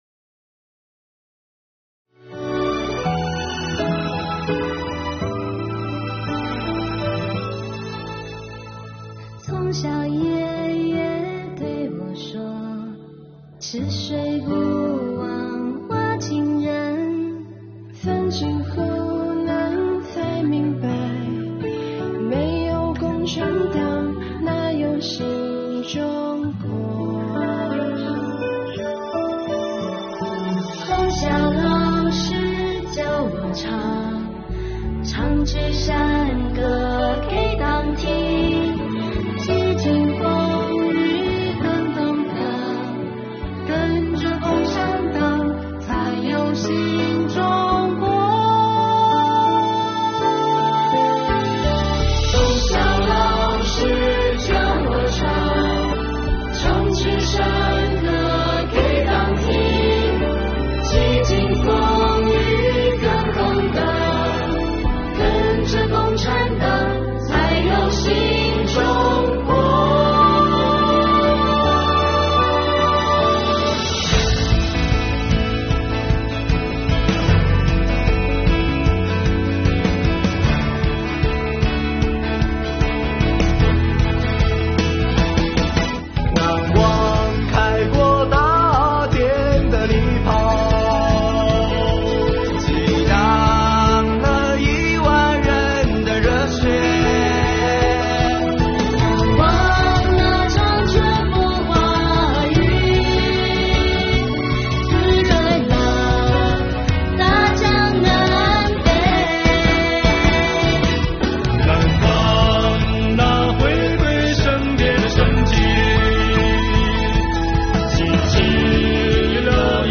税务干部用最质朴的歌声